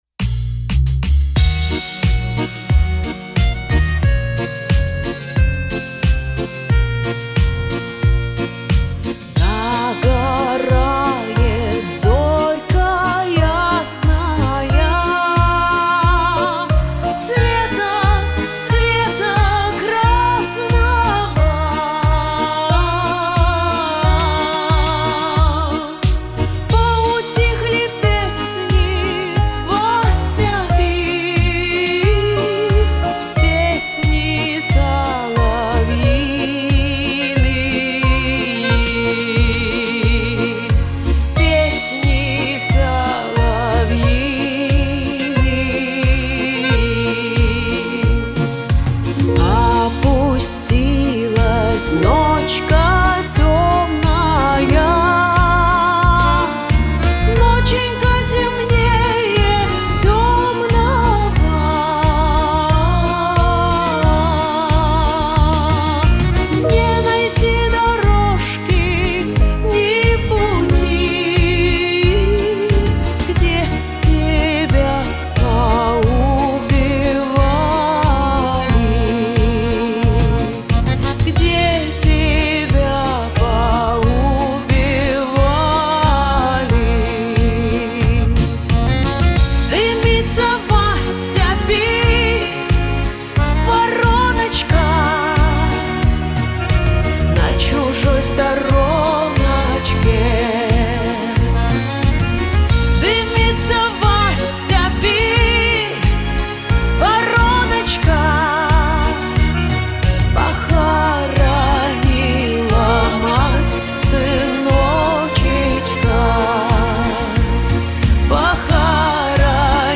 Два варианта "сведения" вокала
Одна и та же песня (фрагмент) с одним, и тем же вокалом. Два варианта его сведения (если, конечно, можно это так назвать).